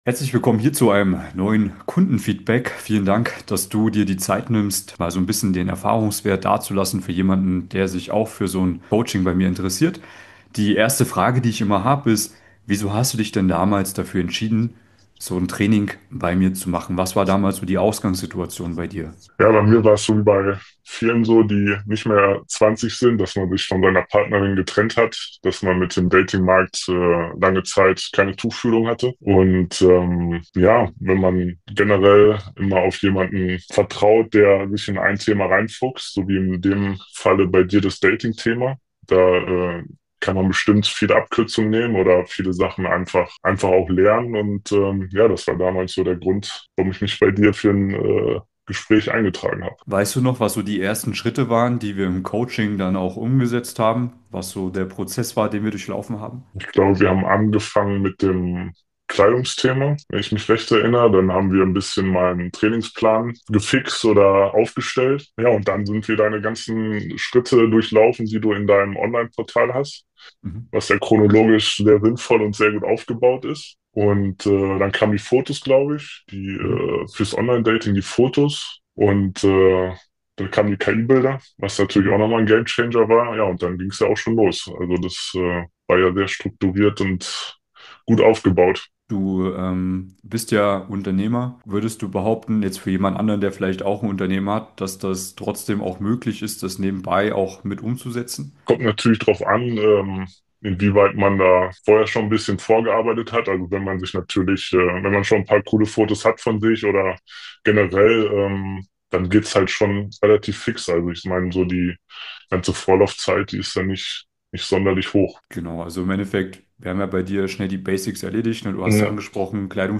In diesem Podcast erzählt ein Coaching-Klient ehrlich, wie er nach